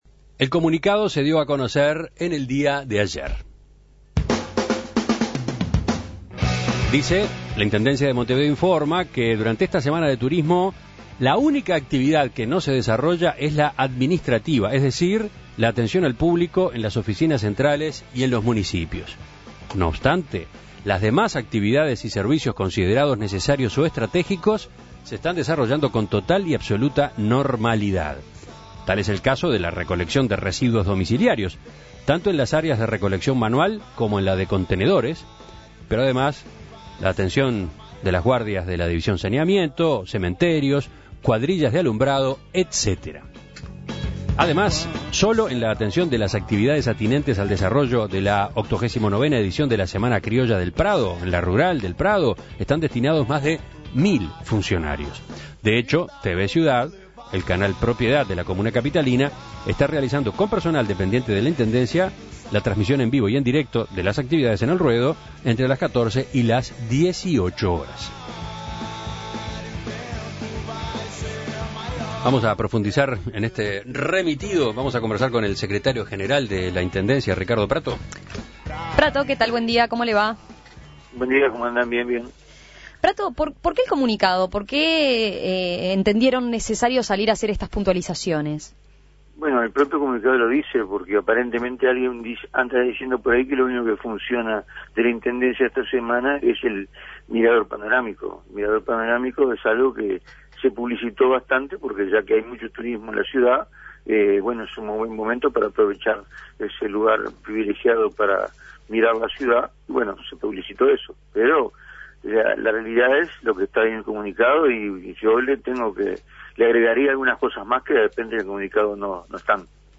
El secretario general de la Intendencia, Ricardo Prato, dijo a En Perspectiva que el comunicado se emitió por la necesidad de aclarar algunos aspectos del trabajo en la Comuna en estos días, incluso cree que los trabajadores en actividad son más de los que se especifican en el comunicado. Prato aclaró que esta dinámica de trabajo es la misma que todos los años y que "no ha causado ningún perjuicio porque la parte de mantenimiento de la ciudad funciona totalmente".